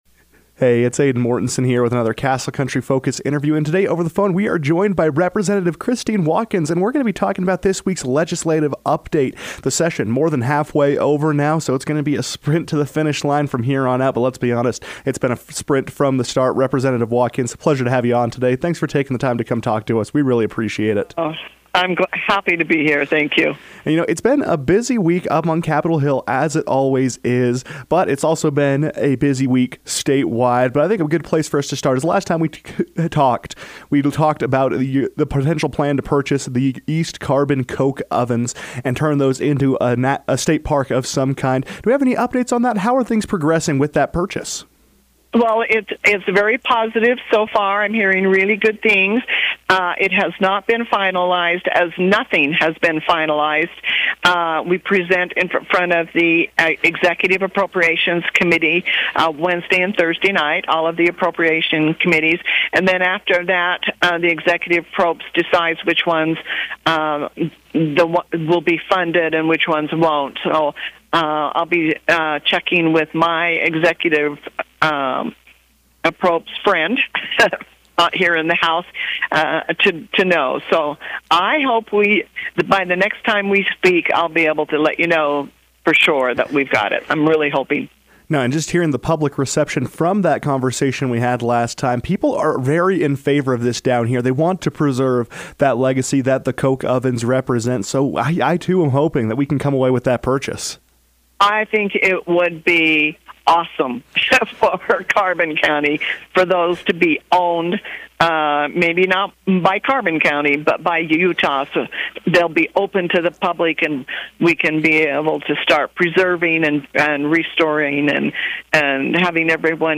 As the 2026 general session of the Utah State Legislature has passed its midway point, Rep. Christine Watkins joined the KOAL newsroom to recap the session thus far, as well as discuss energy developments across the state.